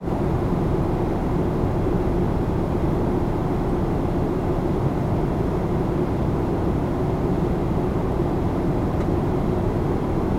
noise_44100Hz.csv ・・・航空機の機内（ジェットエンジン）．いわゆるノイズ．
noise.m4a